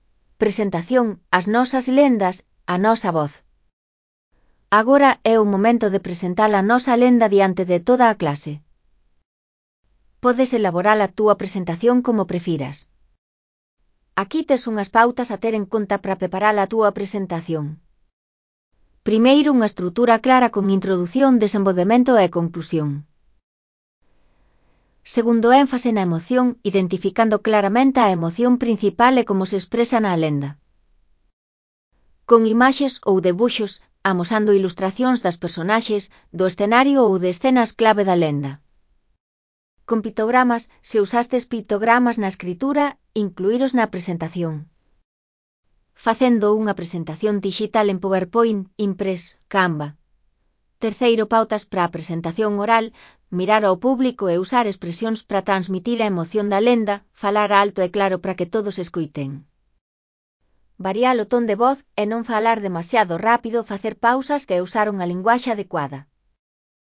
Elaboración propia (proxecto cREAgal) con apoio de IA voz sintética xerada co modelo Celtia.